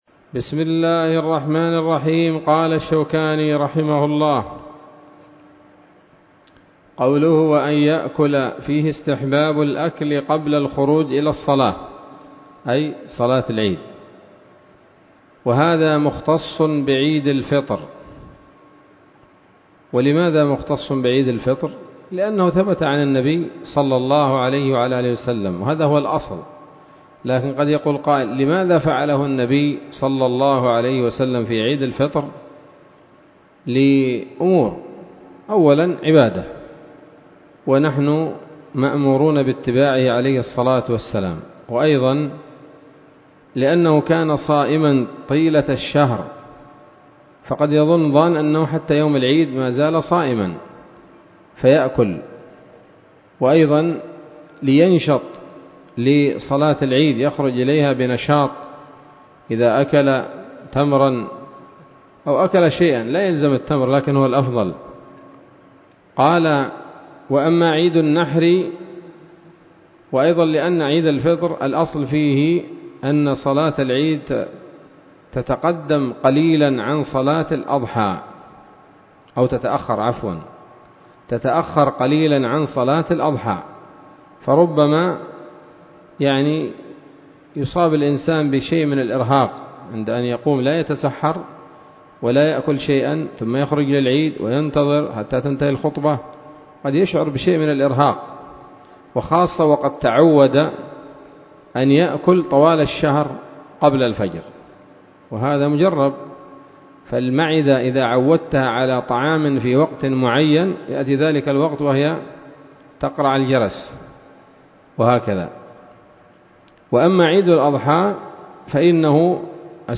الدرس الثالث من ‌‌‌‌كتاب العيدين من نيل الأوطار